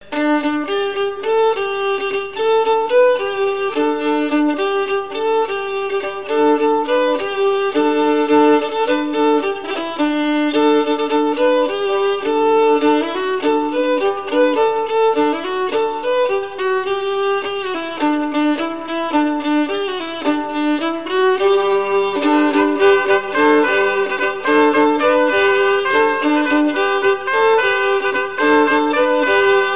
à marcher
rencontre de sonneurs de trompe
Pièce musicale éditée